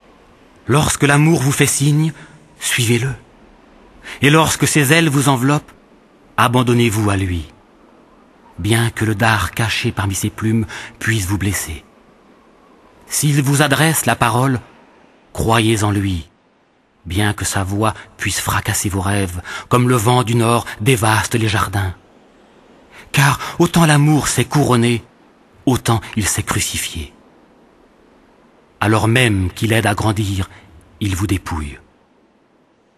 Diffusion distribution ebook et livre audio - Catalogue livres numériques
Lu par Patrice Laffont